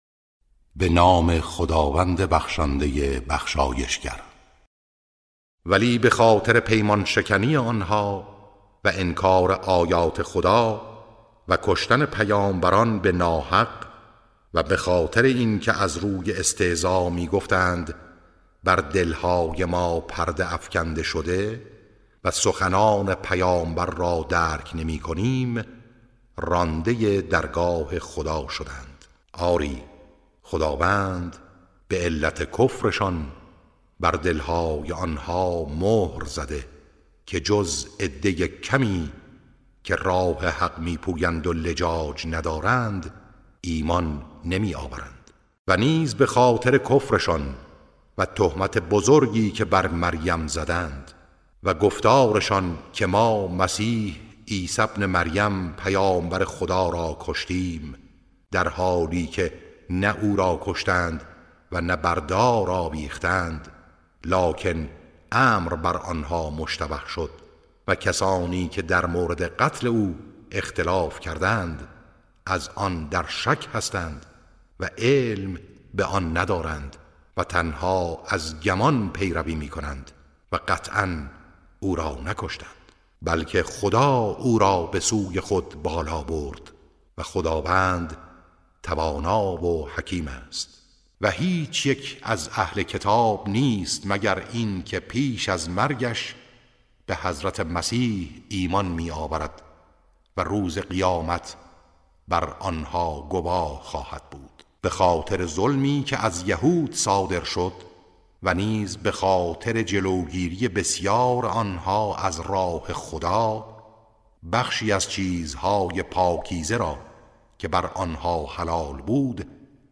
ترتیل صفحه ۱۰۳ سوره مبارکه نساء(جزء ششم)